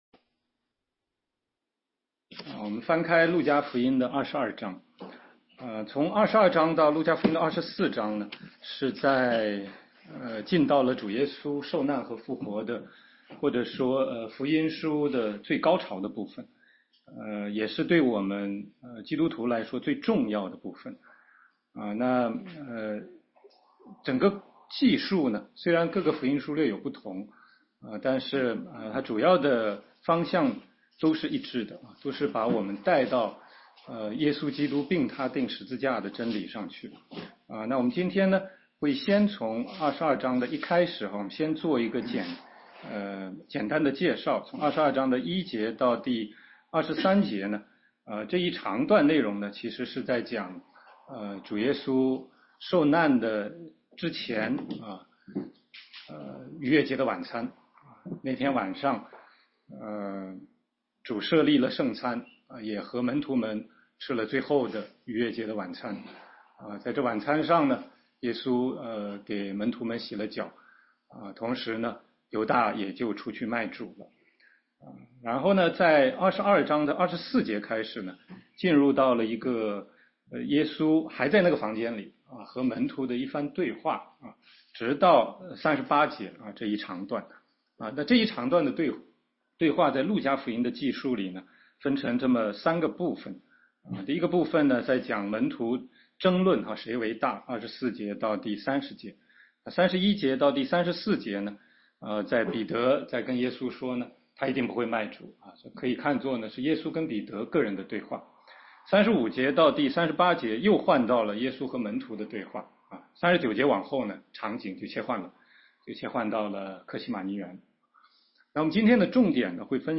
16街讲道录音 - 路加福音22章31-38节：耶稣预言彼得不认主
全中文查经